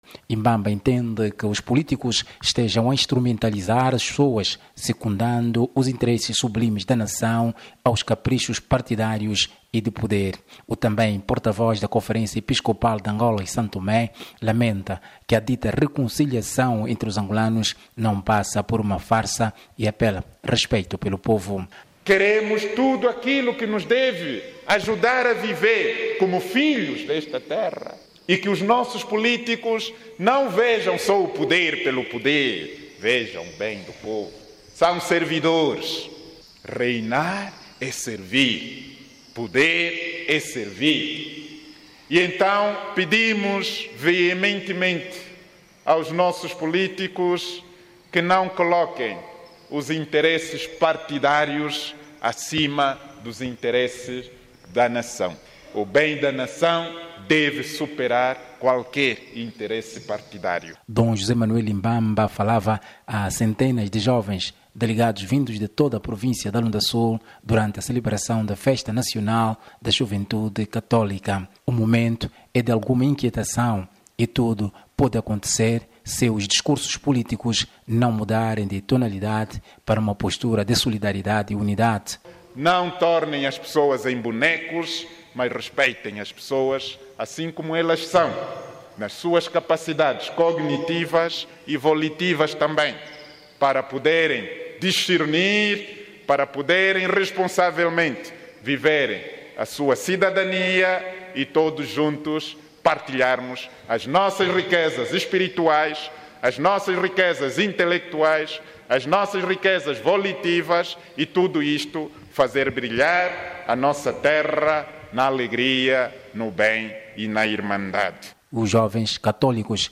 Dom José Manuel Imbamba falava a centenas de jovens delegados vindos de toda a província da Lunda Sul, durante a celebração da festa nacional da Juventude Católica.